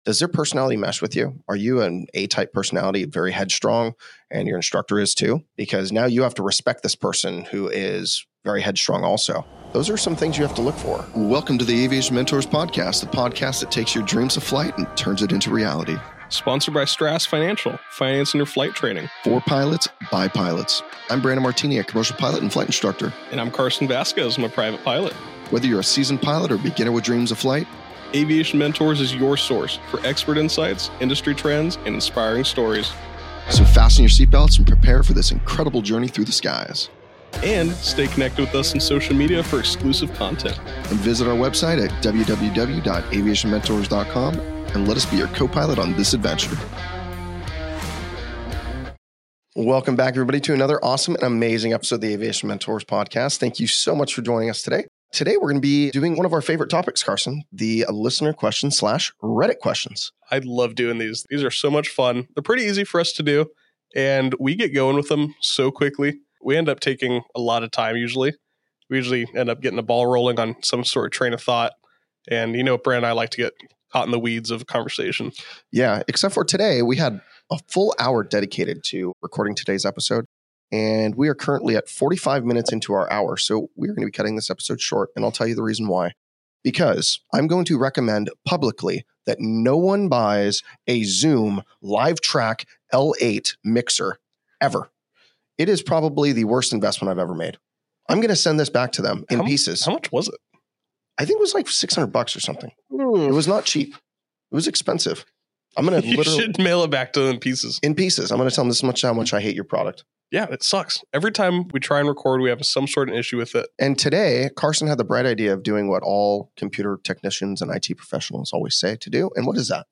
✈ Packed with laugh-filled insights and aviation truths, they share real stories, candid advice, and a few rants—particularly about audio gear misadventures!